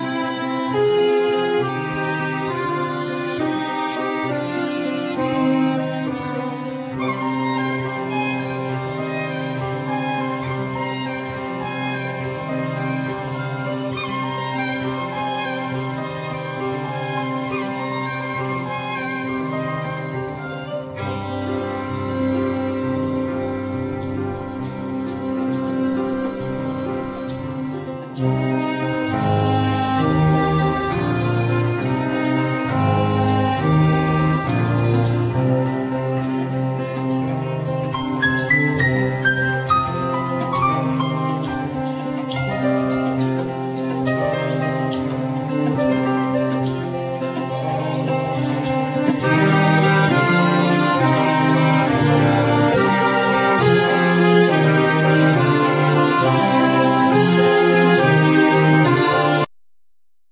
Piano
Violin
Sax
Accordion
Percussions
Clarinet
Tuba